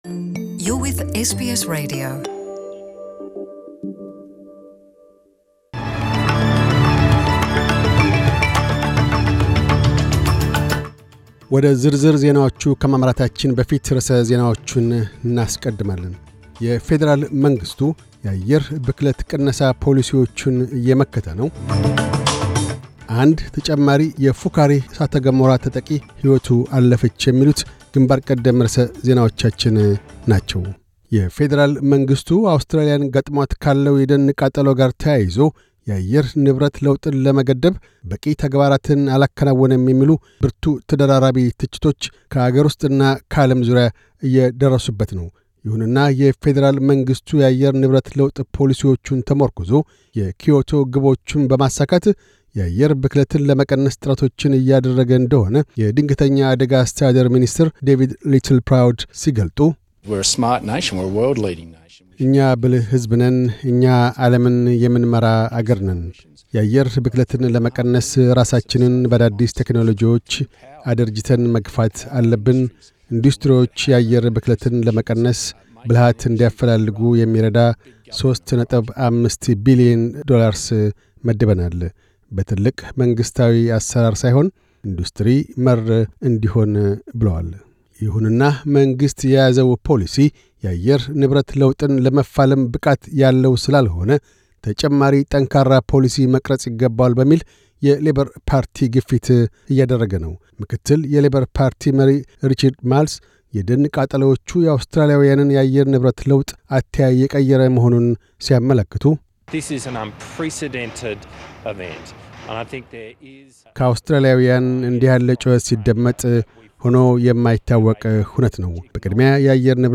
News Bulletin 1311